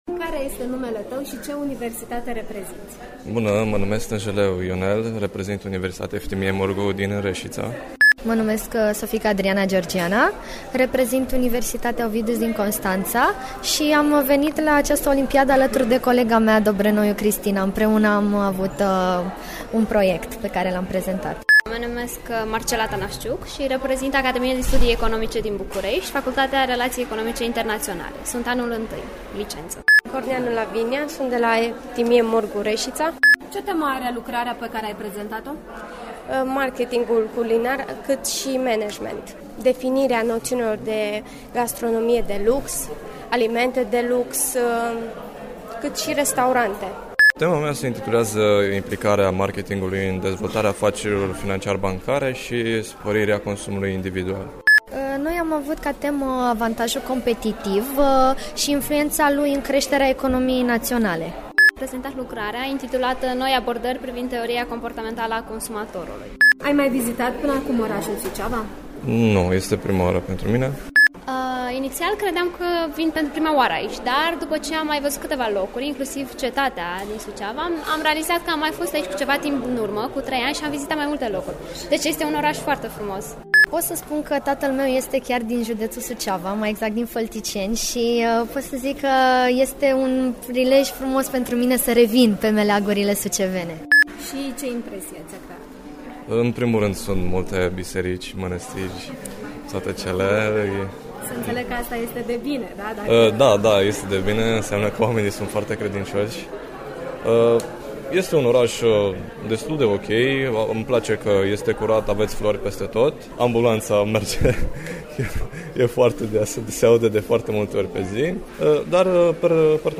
Interviu-Studenti-ONEF.mp3